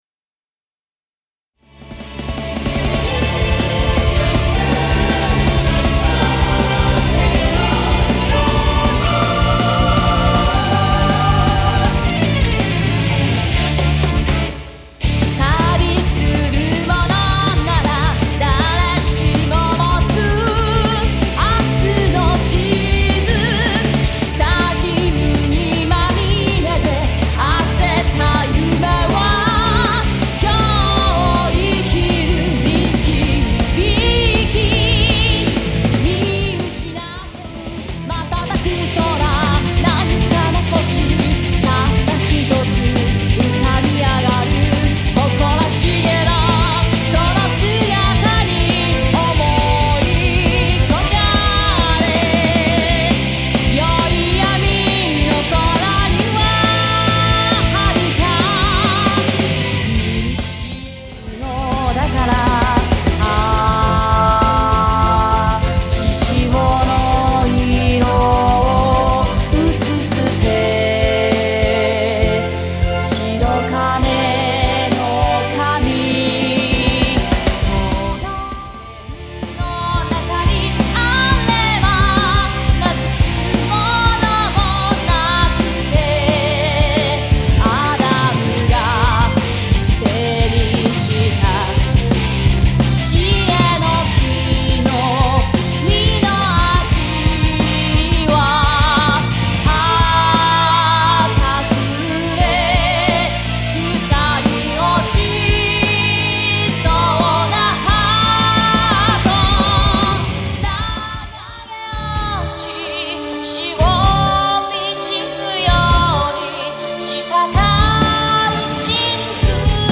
全曲クロスフェード